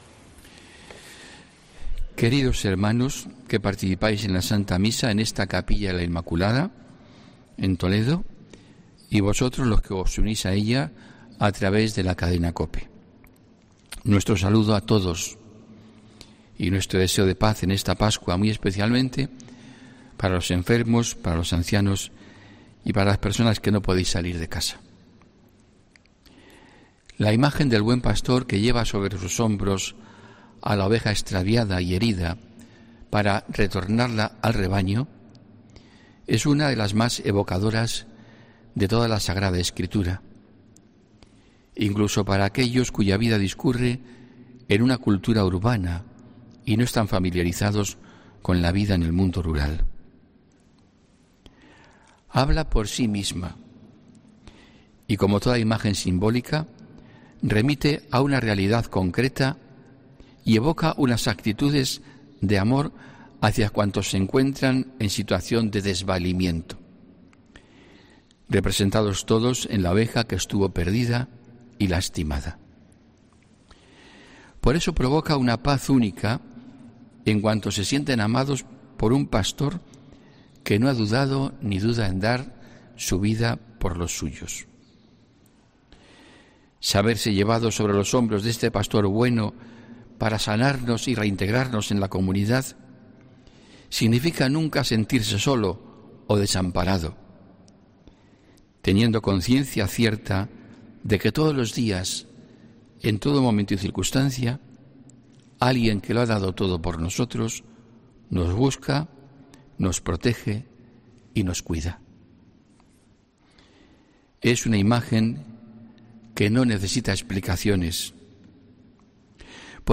HOMILÍA 25 ABRIL 2021